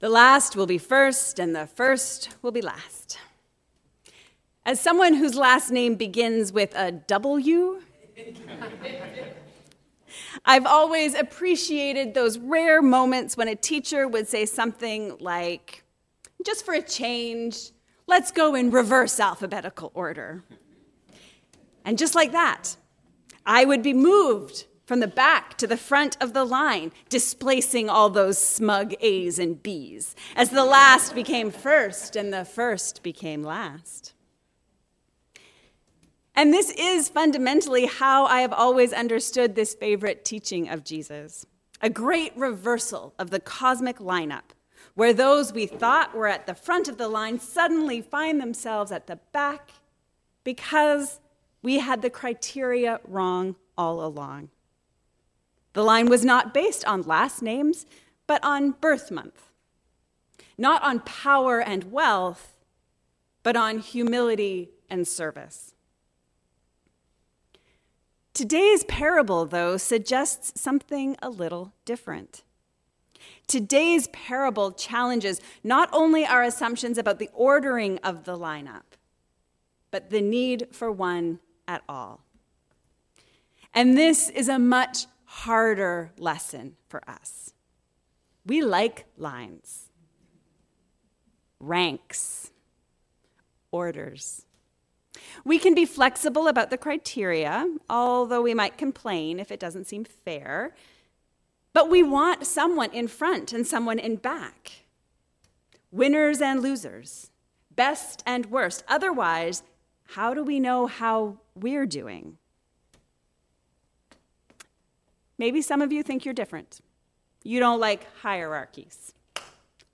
God doesn’t care about the order. A sermon on Matthew 20:1-16